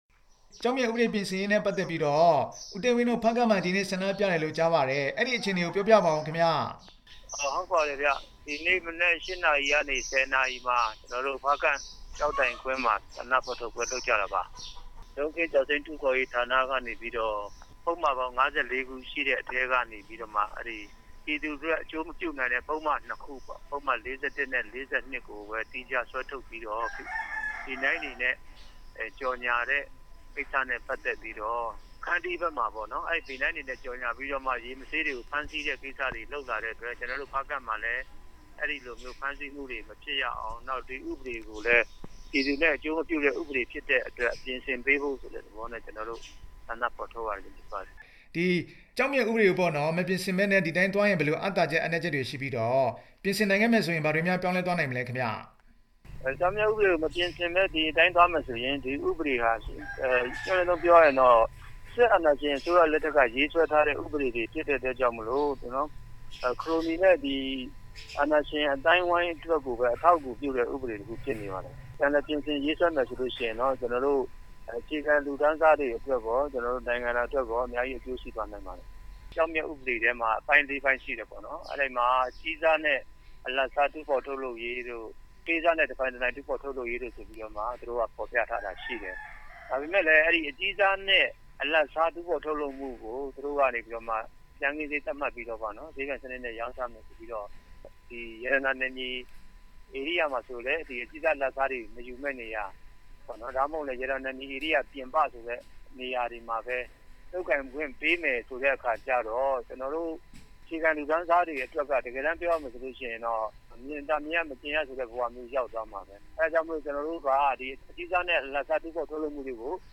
ဆက်သွယ်မေးမြန်းထားပါတယ်။